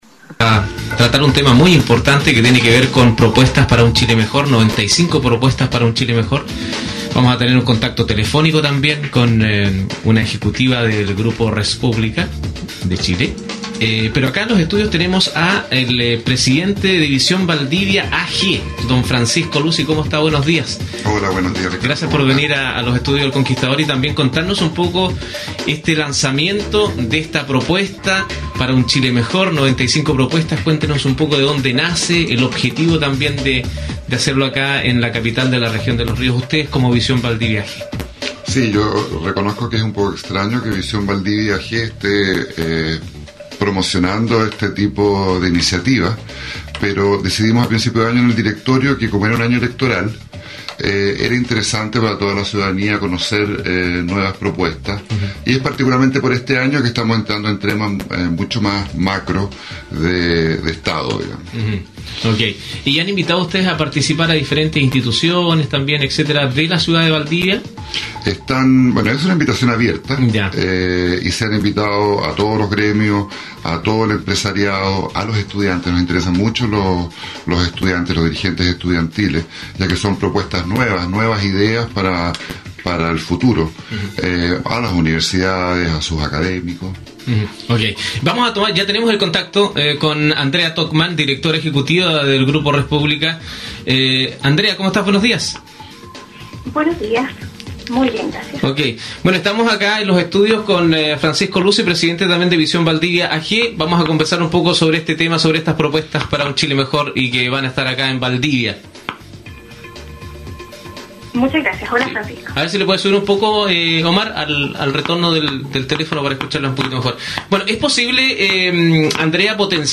ENTREVISTA El Conquistador_05sept13
ENTREVISTA-El-Conquistador_05sept13.mp3